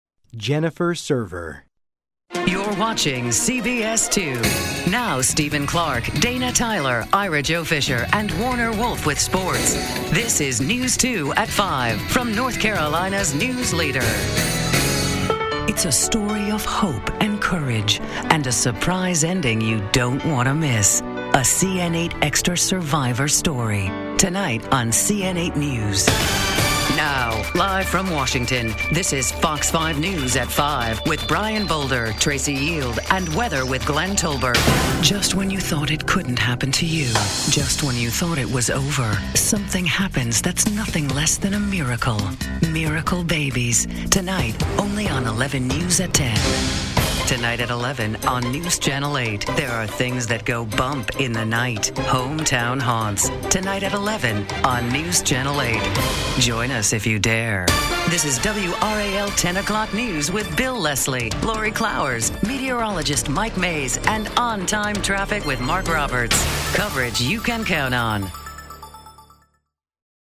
Female VOs